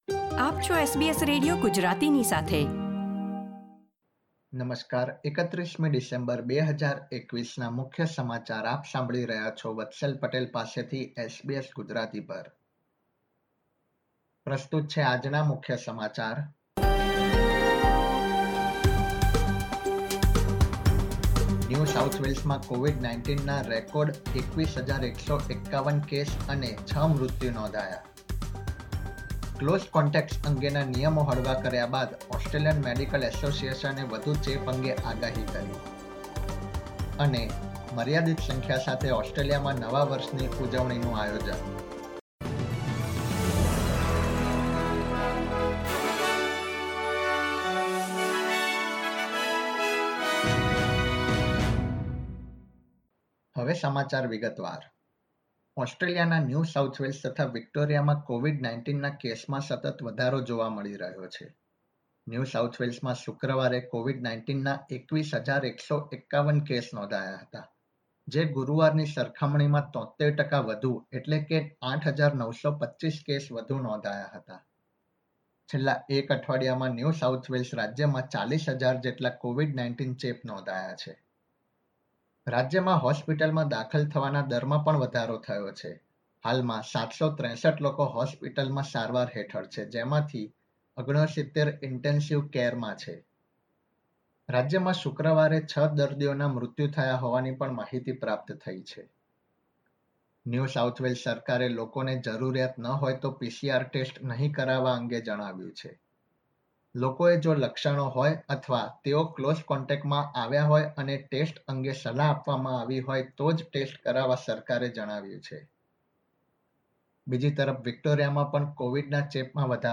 SBS Gujarati News Bulletin 31 December 2021